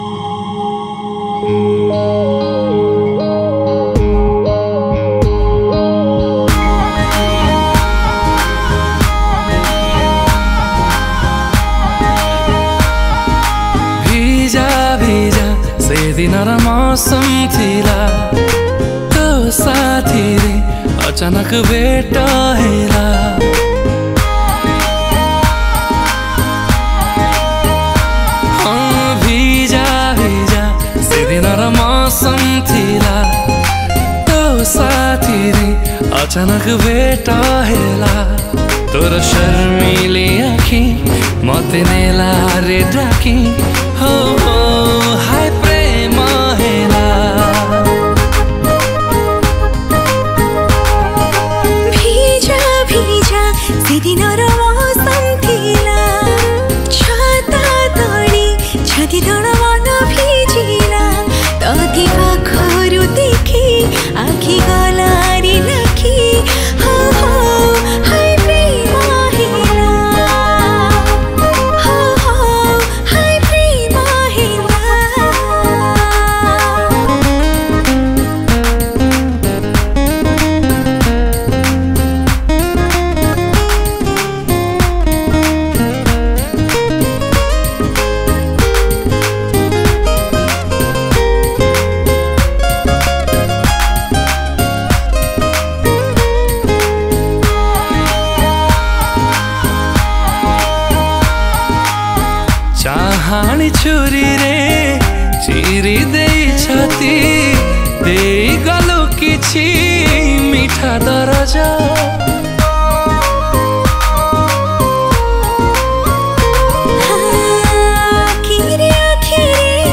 Odia Songs